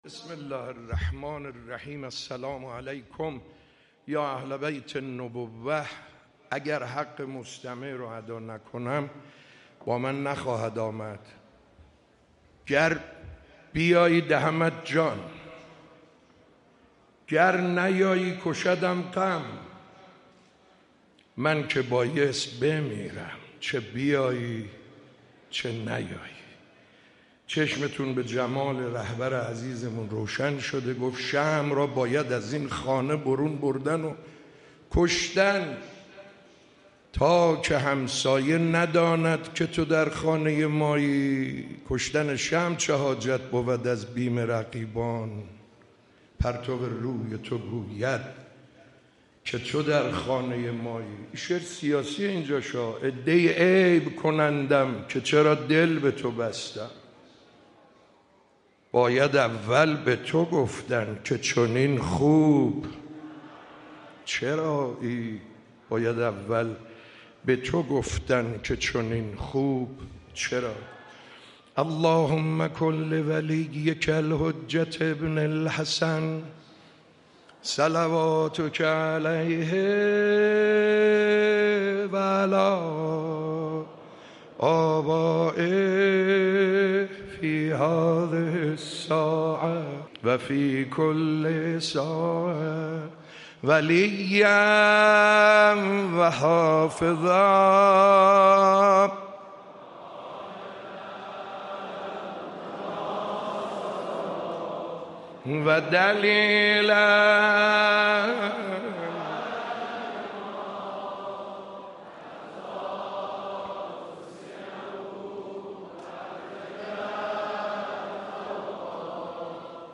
سعید حدادیان در حسینه‌ی امام خمینی (ره) در رثای حضرت زهرا سلام‌الله‌علیها به مرثیه خوانی و مداحی پرداخت
اولین شب از مراسم عزاداری ایام شهادت حضرت فاطمه‌زهرا سلام‌الله‌علیها جمعه 30 آبان 1404 با حضور رهبر انقلاب اسلامی و هزاران نفر از قشرهای مختلف مردم در حسینیه امام خمینی (ره) برگزار شد.
در این مراسم سعید حدادیان در رثای حضرت زهرا سلام‌الله‌علیها به مرثیه خوانی و مداحی پرداخت که در ادامه گوش می‌دهید.